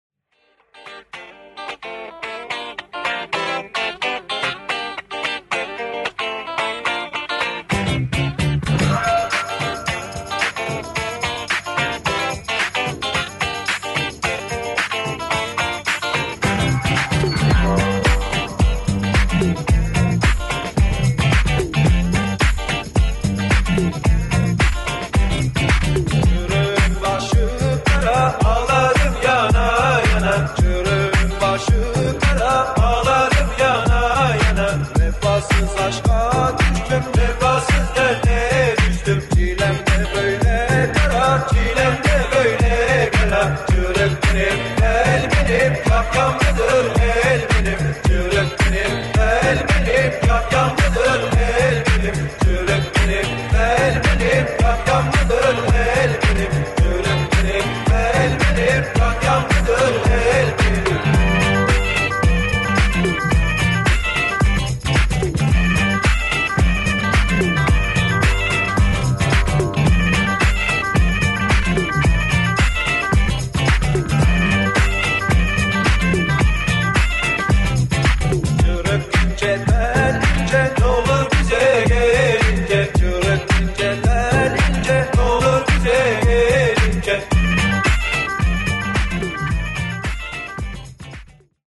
今作も異国情緒全開のカルトなネタのオンパレードです！